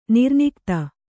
♪ nirnikta